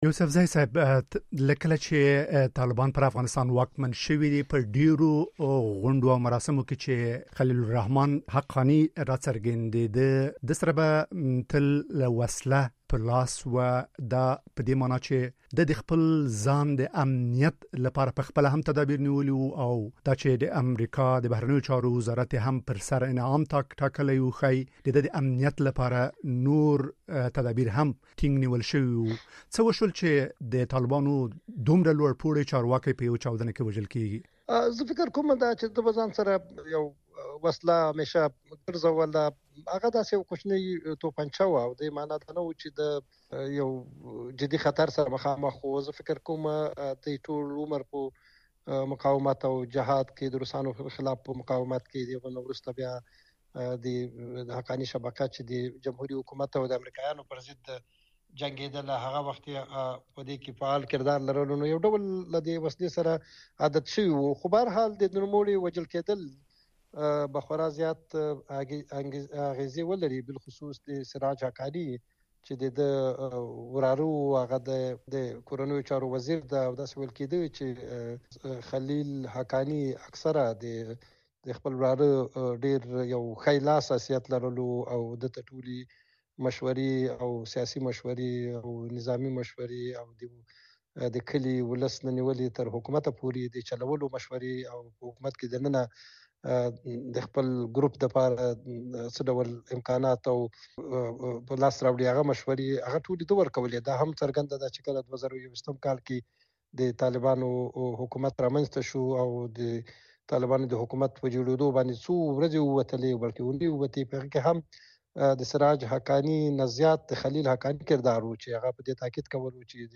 ژورنالست او د چارو کتونکی